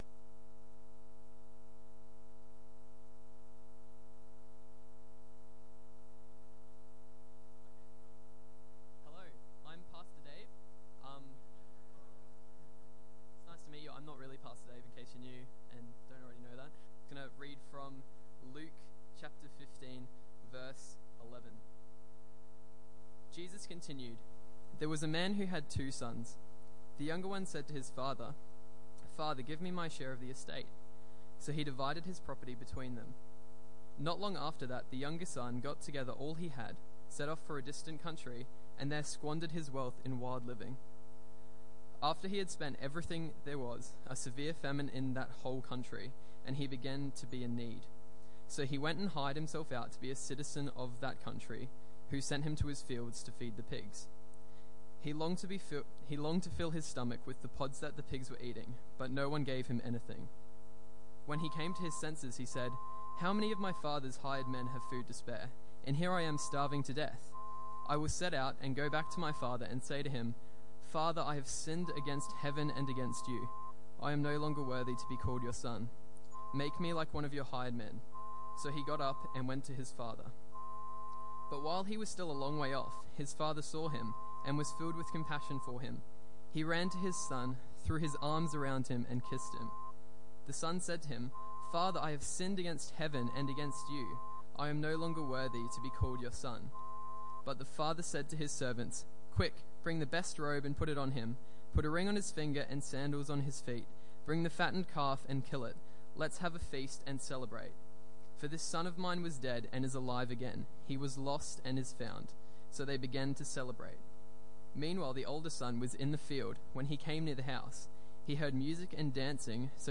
Luke 15:11-32 Tagged with Sunday Evening Audio (MP3) 18 MB Previous The Great Invitation Next Showing Grace To An Immoral World